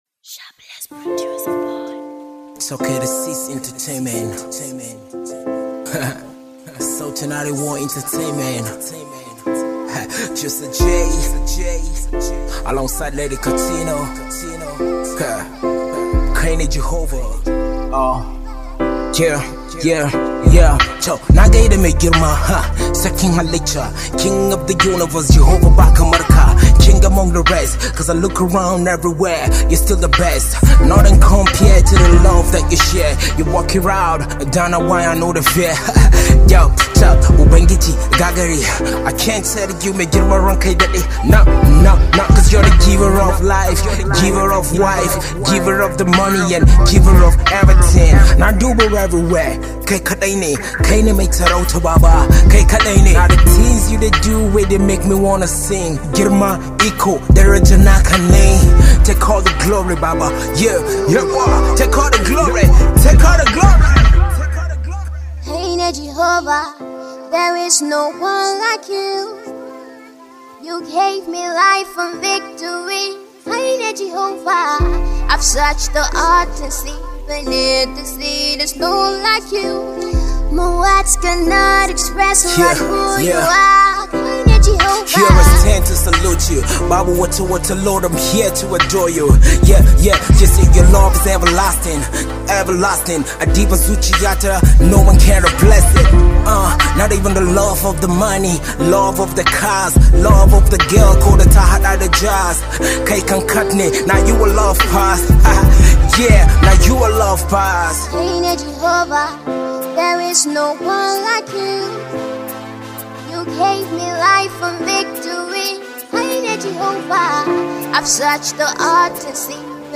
RnB
RAP